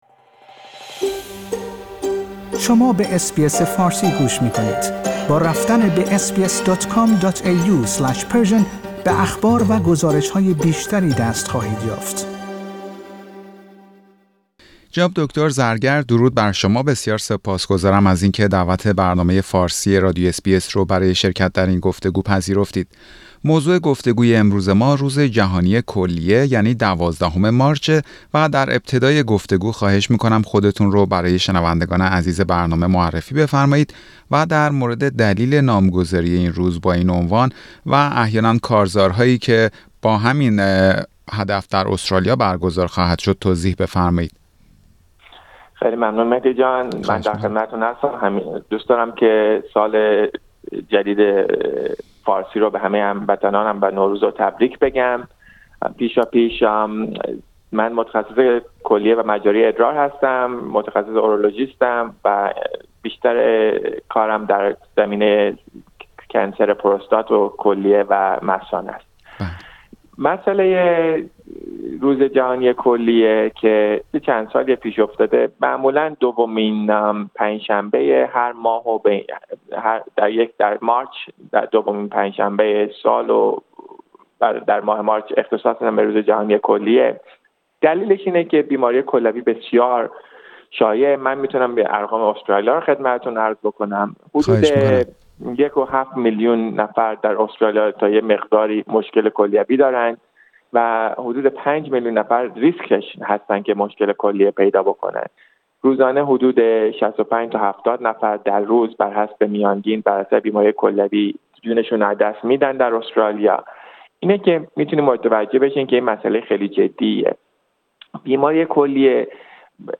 برنامه فارسی رادیو اس بی اس در همین خصوص گفتگویی داشت